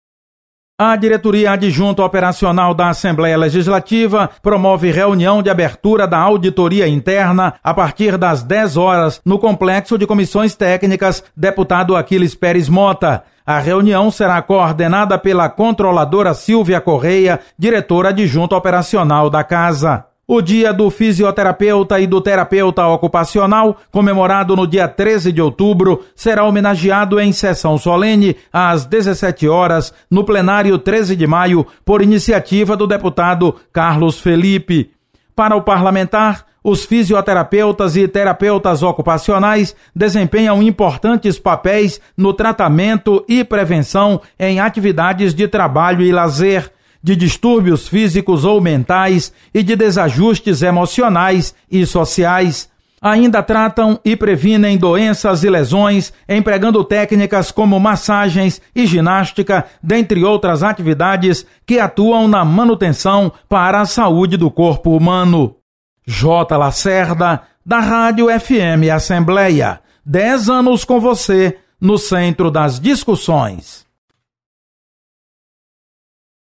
Você está aqui: Início Comunicação Rádio FM Assembleia Notícias agenda